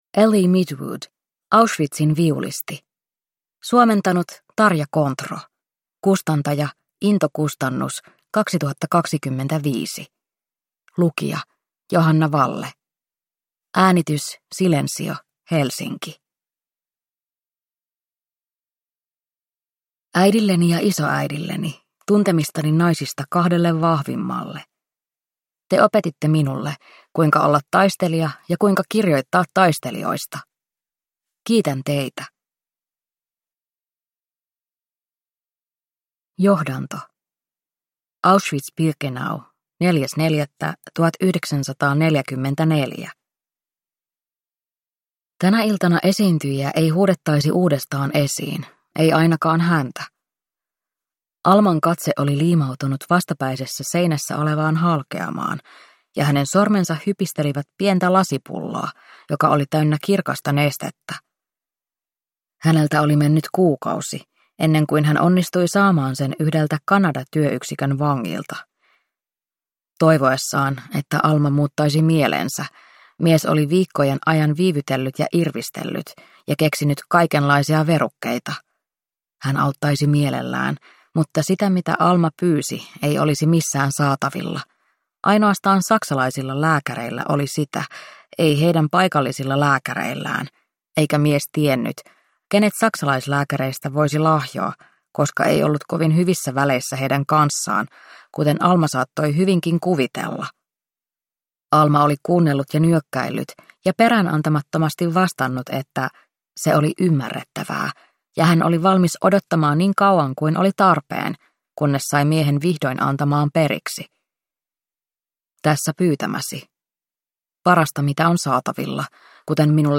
Auschwitzin viulisti – Ljudbok